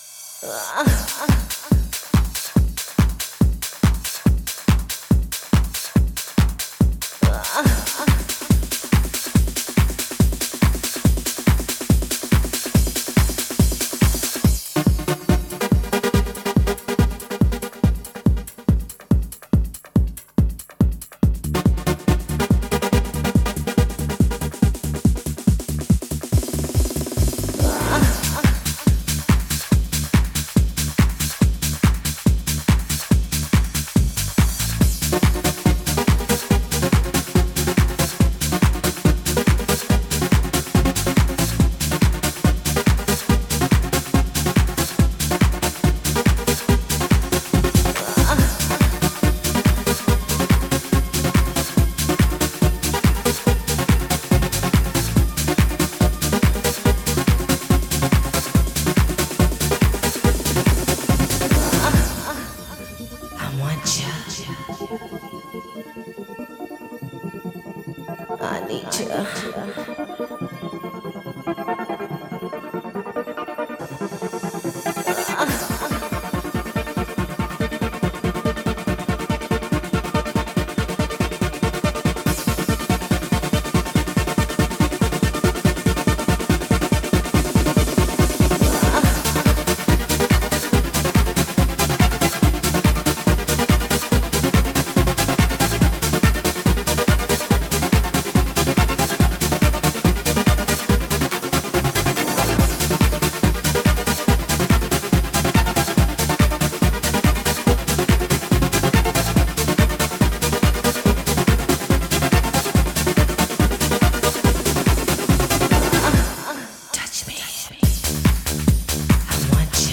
Genre: Dream.